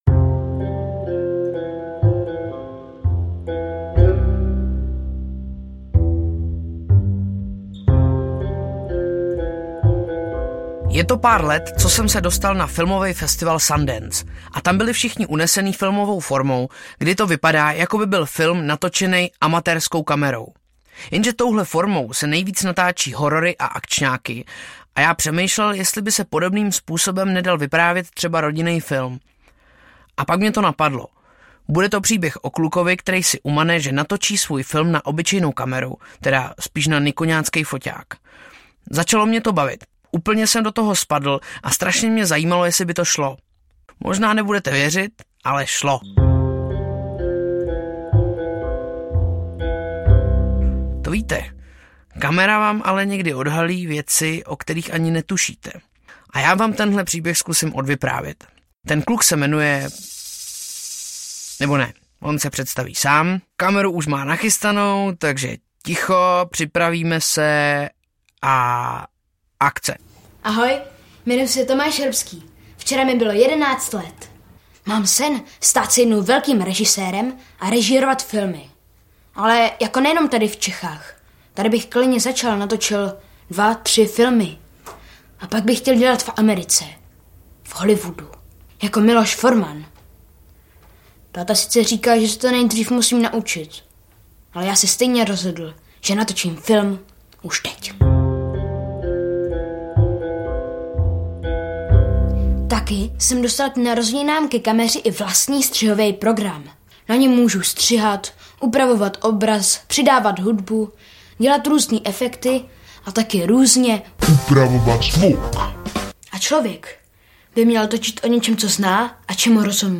Pojedeme k moři audiokniha
Ukázka z knihy
Jiří Mádl vypráví svůj film!
Dvojí debut herce Jiřího Mádla jako režiséra celovečerního hraného filmu a jako scénáristy příběhu o jedné obyčejné rodině nahlížený očima desetiletého Tomáše, který chce být dalším Milošem Formanem. Dále účinkují: Ondřej Vetchý, Jaroslava Pokorná, Lucie Trmíková, Miroslav Táborský.